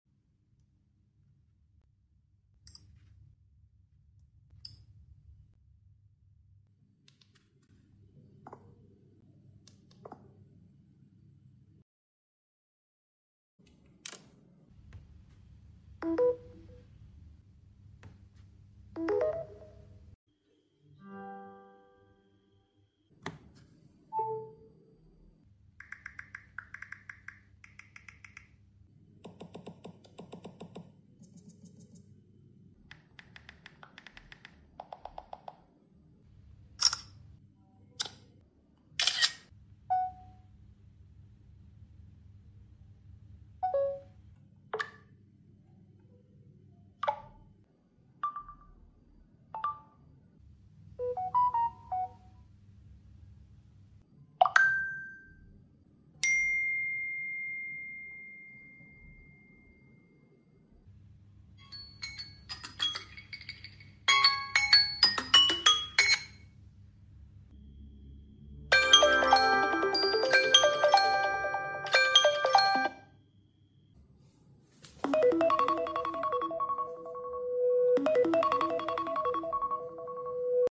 Samsung Google Pixel and iPhone sound effects free download
Samsung Google Pixel and iPhone UI sounds